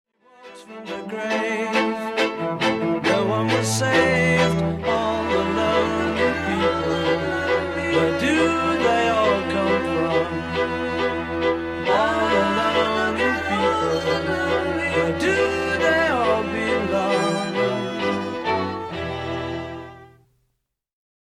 Some of you already heard: it’s not really a new theme, but the rocket
As I write this I find that I really want to re-record that part, making it more espressivo, sadder…